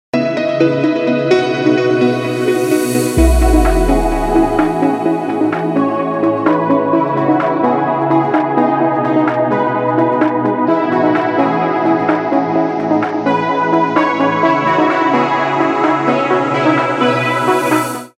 رینگتون پر انرژی و بی کلام
برداشتی آزاد از موسیقی های بی کلام خارجی